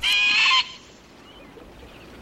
• SINGLE BIRD SQUAWK ECHO.wav
SINGLE_BIRD_SQUAWK_ECHO_WAj.wav